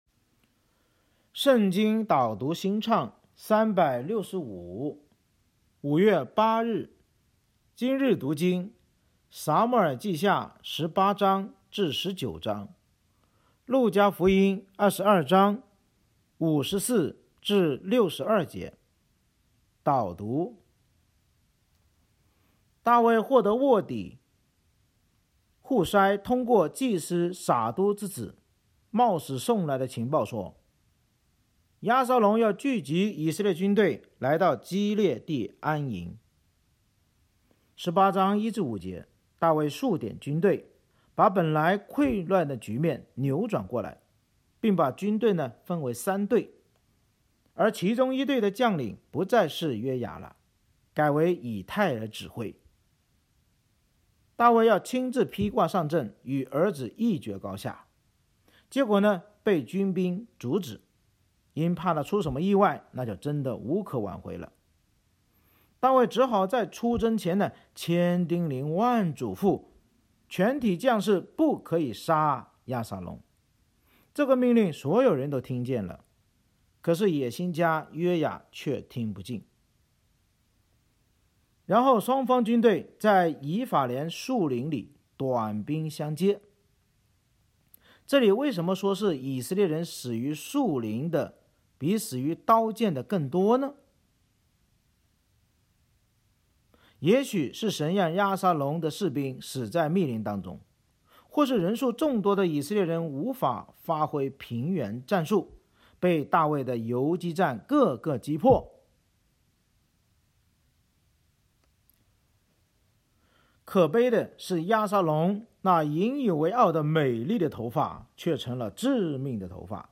【导读新唱365】朗读5月8日.mp3